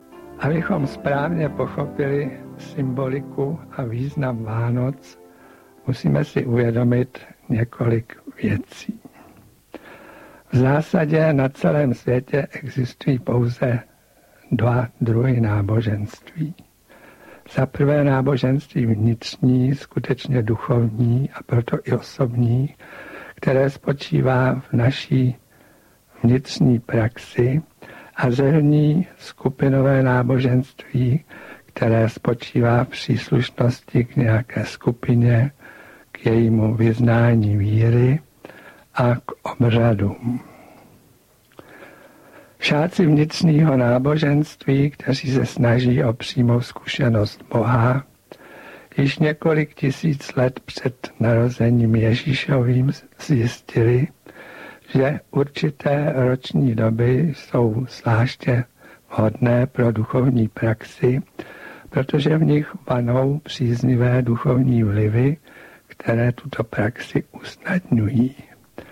Nahrávka vznikla původně jako pořad pro Český rozhlas v prosinci roku 1997. 2) Duchovní symbolika Velikonoc -Velikonoční svátky jsou významným obdobím z hlediska duchovního vývoje.
Nejedná se o studiovou nahrávku.
Audiokniha